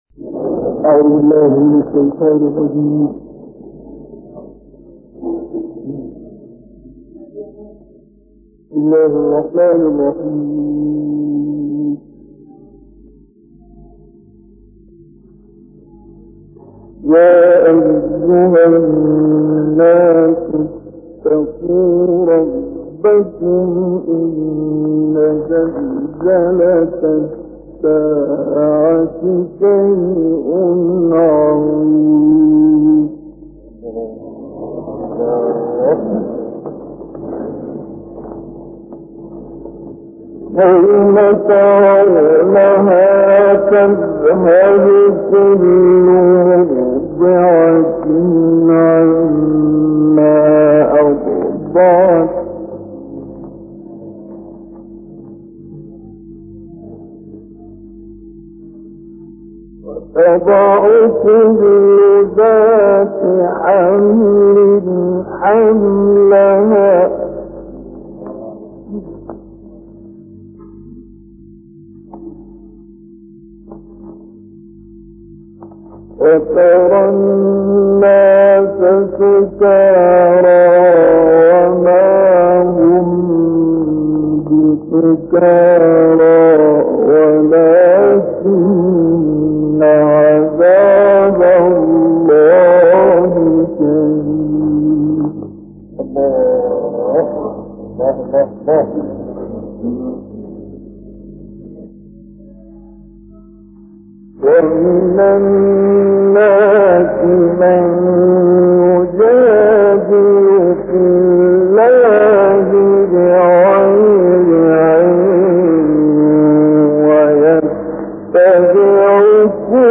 قدیمی‌ترین تلاوت موجود از مصطفی اسماعیل صوت - تسنیم